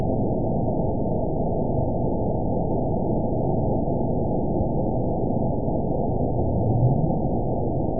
event 917649 date 04/11/23 time 13:31:02 GMT (2 years, 1 month ago) score 9.22 location TSS-AB01 detected by nrw target species NRW annotations +NRW Spectrogram: Frequency (kHz) vs. Time (s) audio not available .wav